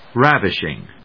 音節ráv・ish・ing 発音記号・読み方
/ˈrævɪʃɪŋ(米国英語)/